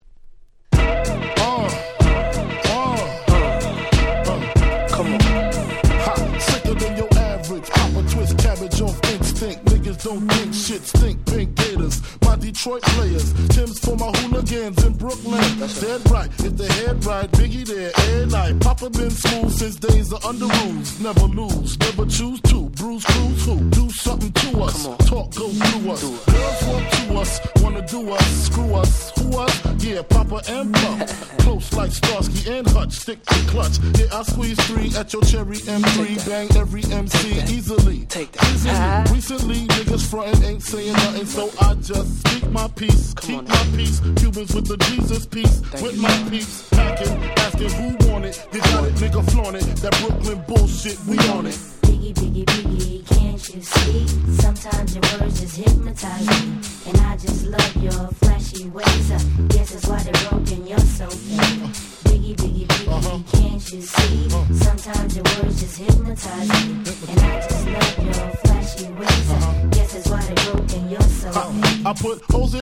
90's Hip Hop Super Classics !!